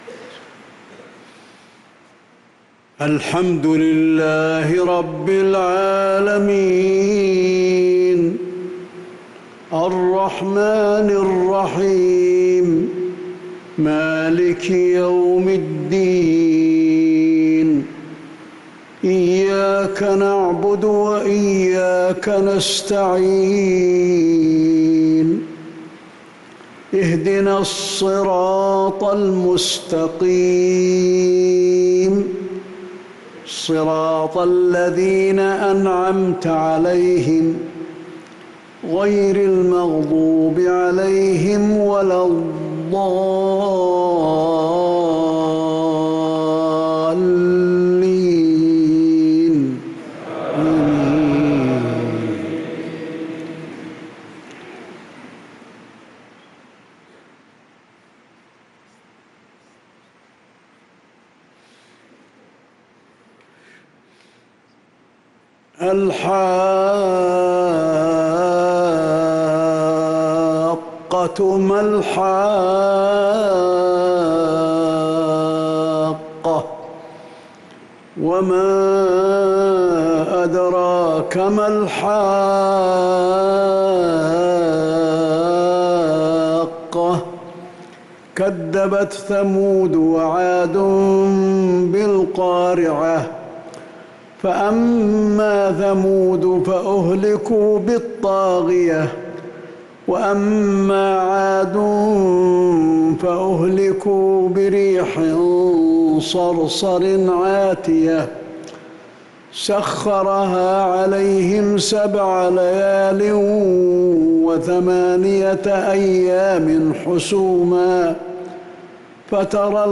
صلاة العشاء للقارئ علي الحذيفي 13 شعبان 1445 هـ